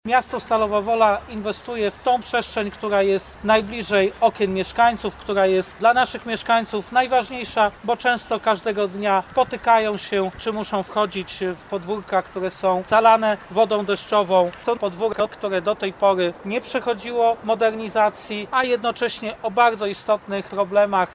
Prezydent Stalowej Woli Lucjusz Nadbereżny podkreślał, że inwestycja jest ważna dla miasta, bo jest ważna dla mieszkańców, którzy potrzebują nie tylko rozwoju gospodarczego Stalowej Woli, ale też przyjaznej przestrzeni z której mogą korzystać na co dzień: